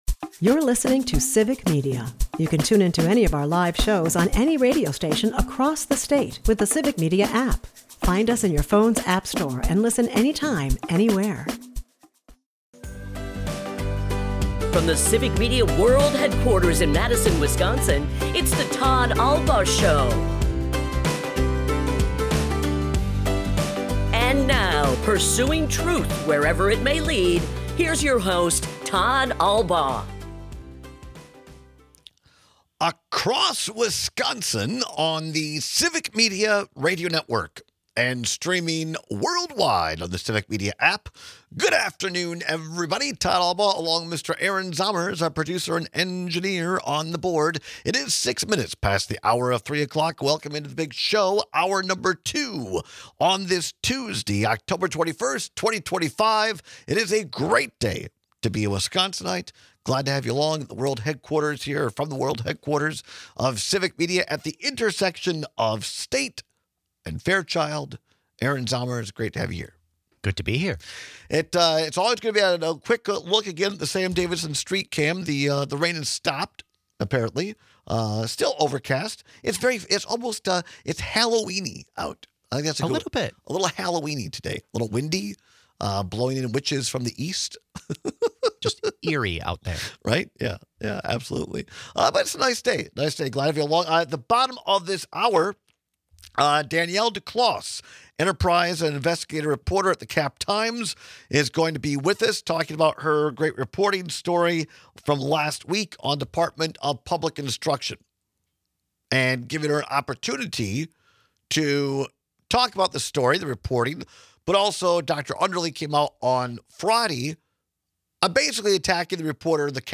We take your calls and texts on which is harder to stifle.&nbsp